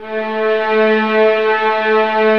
VIOLINS BN-L.wav